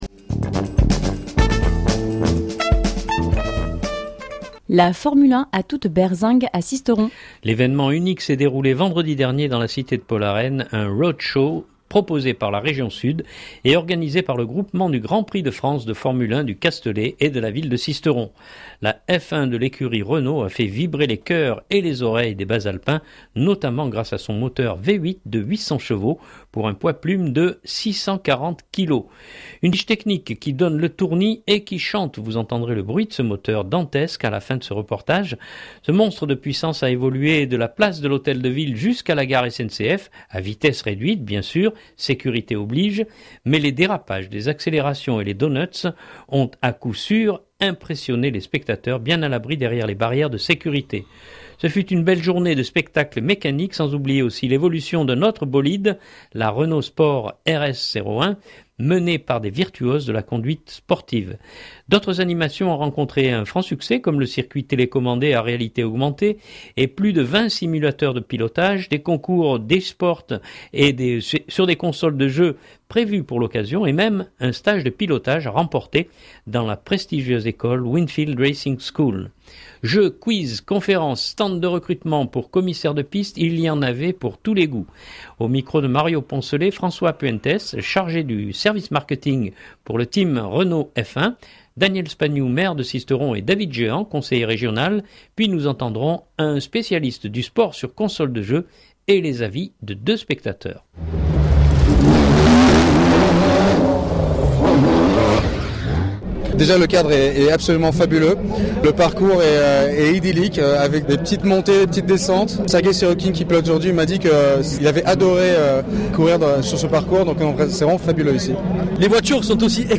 Vidéo et sons enregistrés sur place !
Vous entendrez le bruit de ce moteur dantesque à la fin de ce reportage.